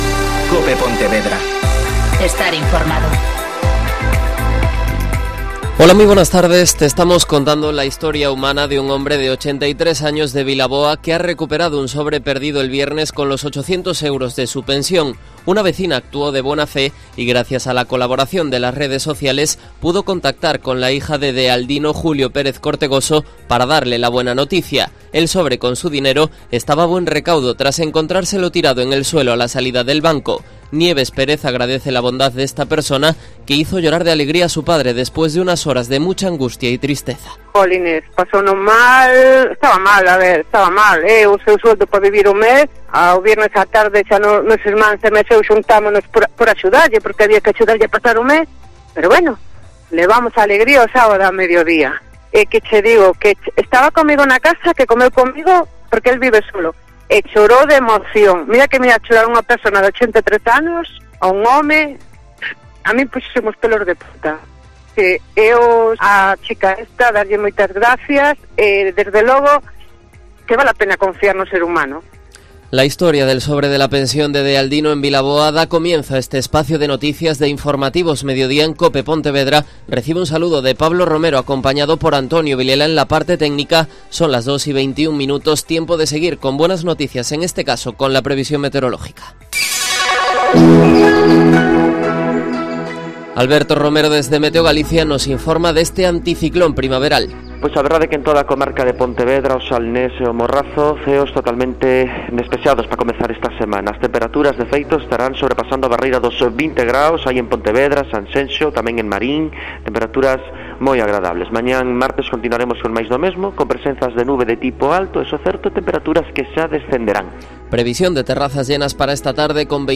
Mediodía COPE Pontevera (Informativo 14:20h)